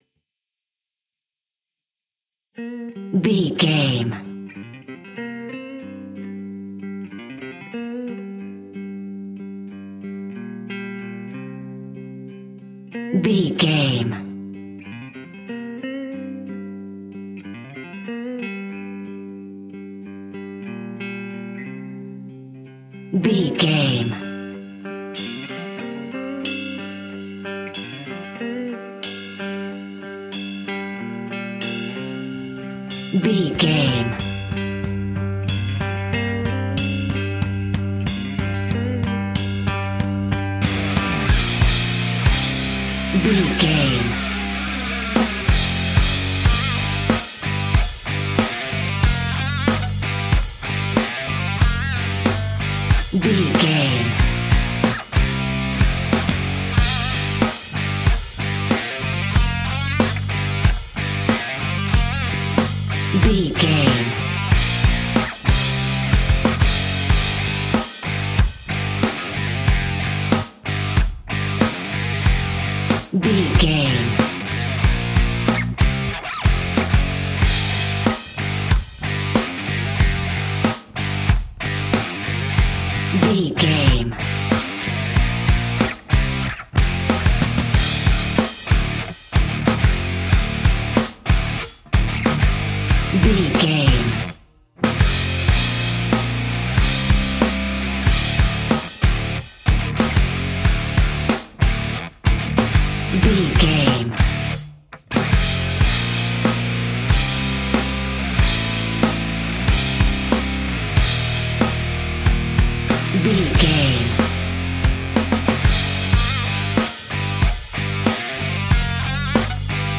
Ionian/Major
energetic
driving
heavy
aggressive
electric guitar
bass guitar
drums
hard rock
distortion
rock instrumentals
distorted guitars
hammond organ